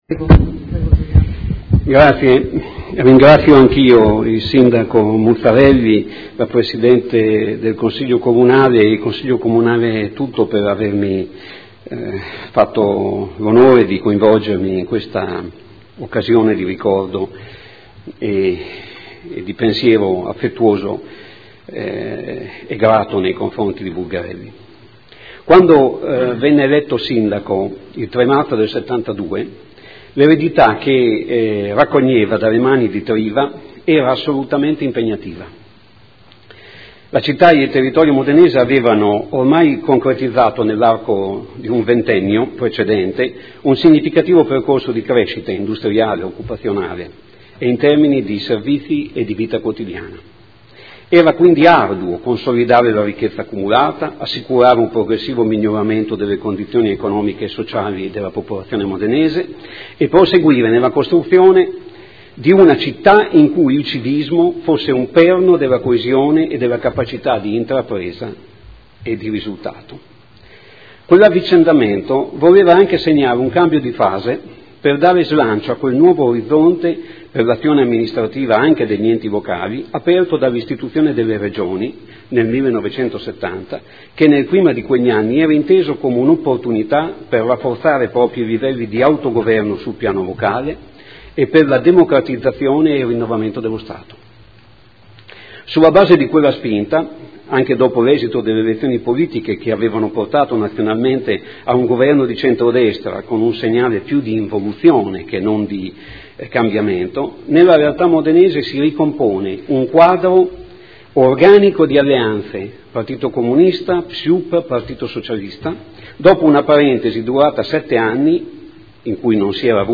Seduta del 2 luglio. Commemorazione del Sindaco Germano Bulgarelli ad un anno dalla scomparsa.